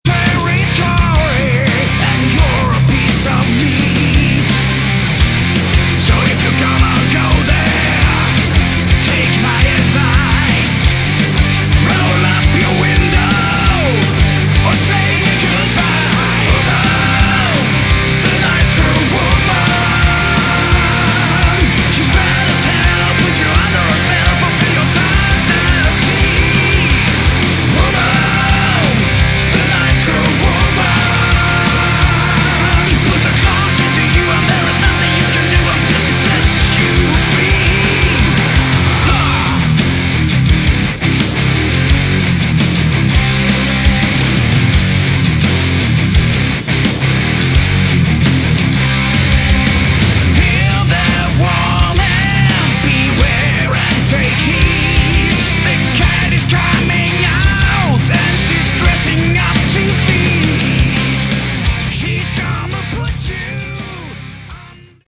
Category: Melodic Metal
vocals
guitars
drums
bass